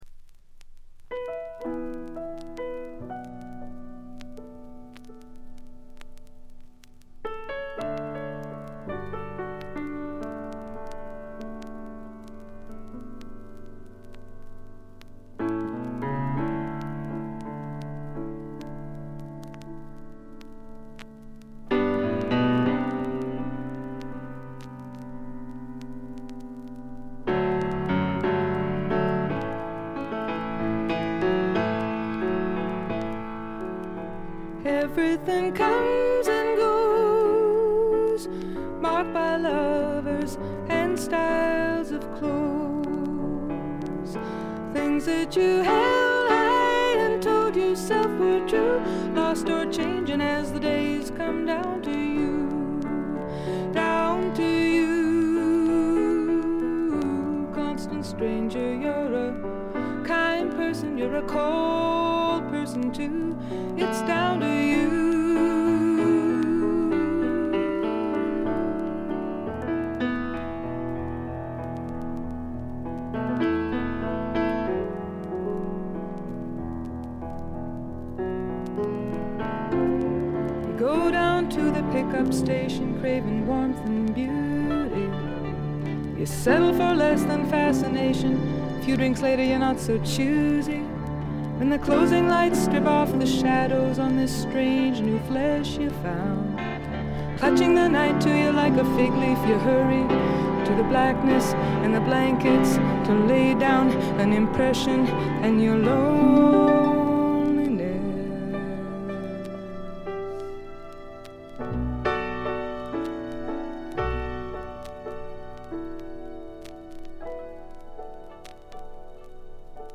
静音部でチリプチが目立つところがありますが（特にA1冒頭とB2）まずまず良好に鑑賞できると思います。
試聴曲は現品からの取り込み音源です。